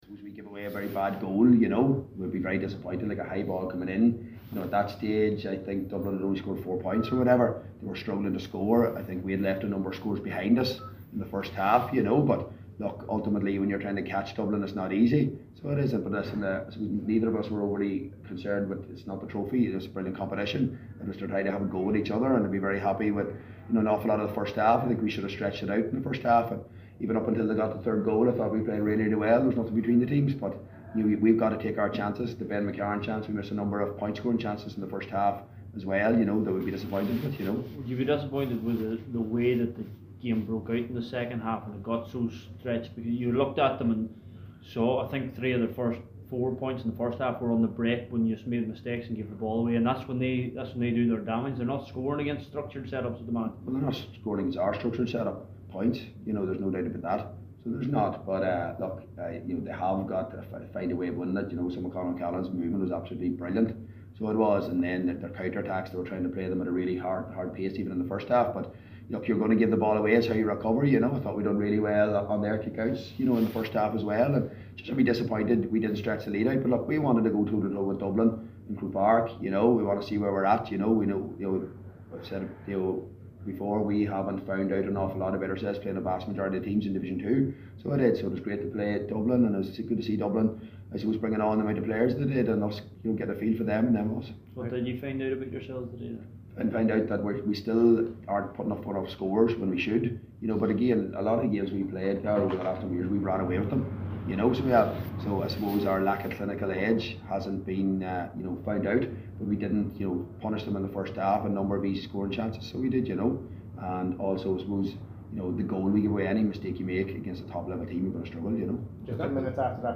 Rory Gallagher reflects following Derry’s defeat to Dublin in Division 2 League final
The Oak Leaf manager said that Derry ‘still have a lack of clinical edge’ highlighting that you can’t make mistakes against the top teams.